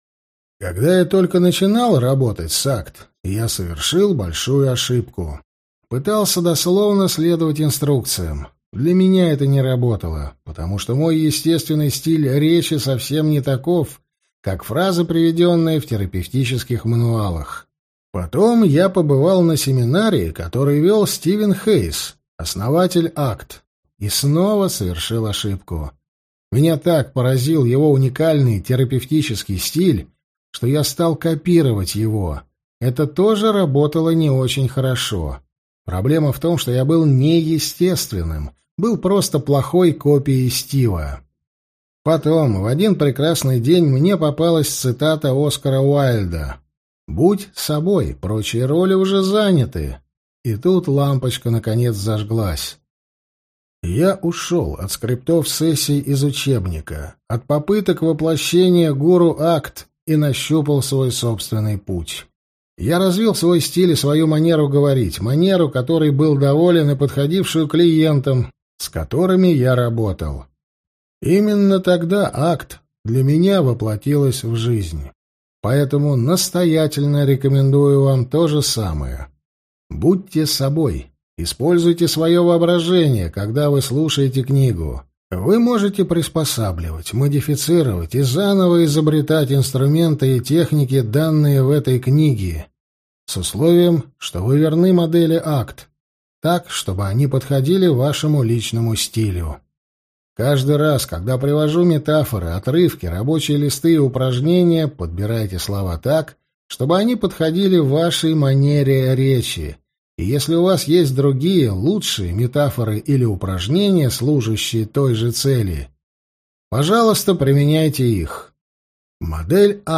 Аудиокнига Полное наглядное пособие по терапии принятия и ответственности | Библиотека аудиокниг